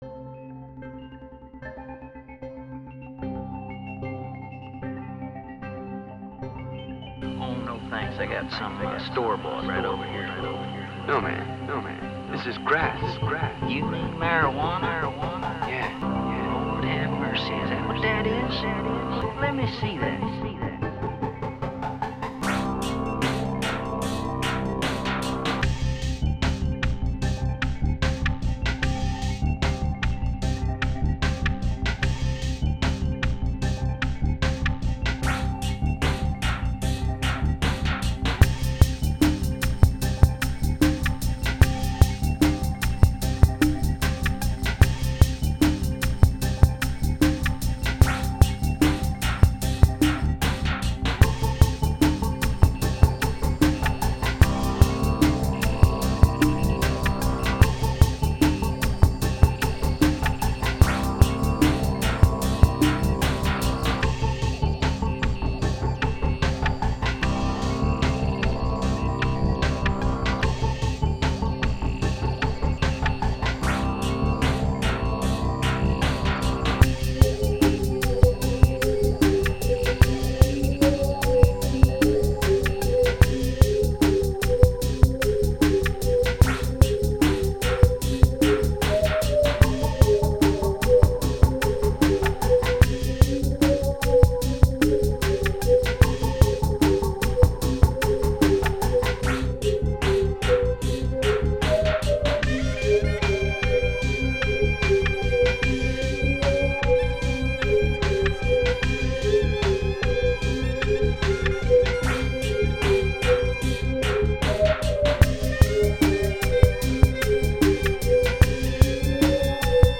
chill-out genre
um chill-out cremoso